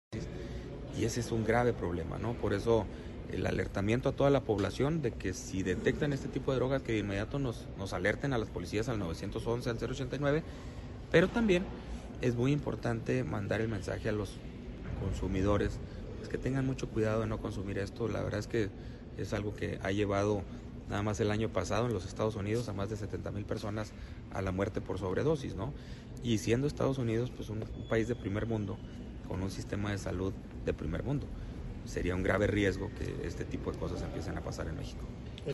AUDIO: GILBRETO LOYA CHÁVEZ, SECRETARÍA DE SEGURIDAD PÚBLICA DEL ESTADO (SSPE)